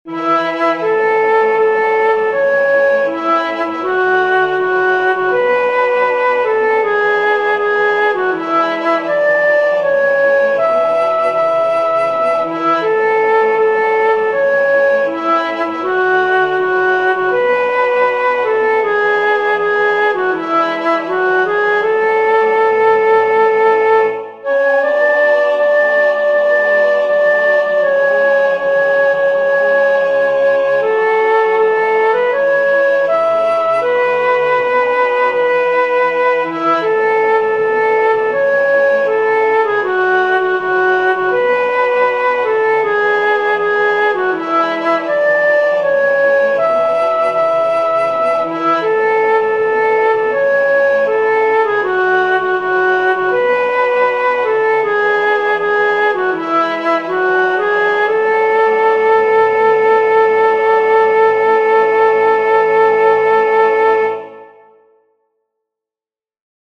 Esta canción popular tiene una estructura muy sencilla, tipo A-B-A’.
El tempo aparece indicado como Moderato.
o-sari-mares-soprano1.mp3